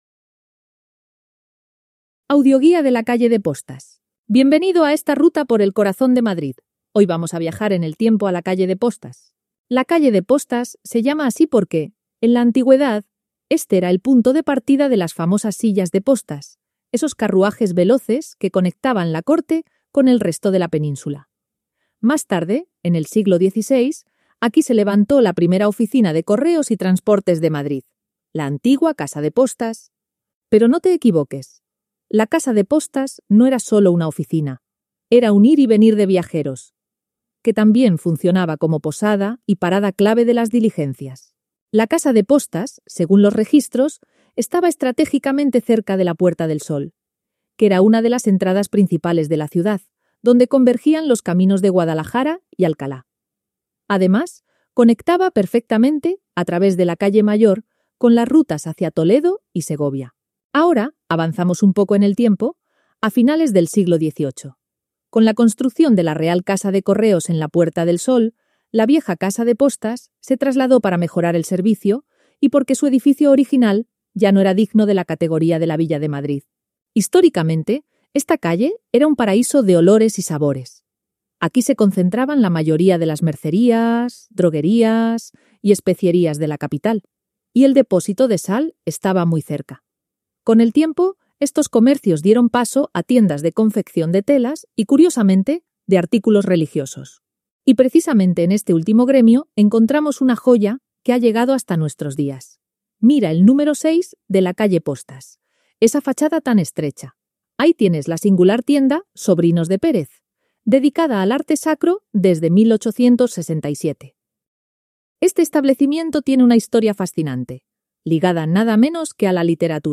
Audioguía: La Calle de Postas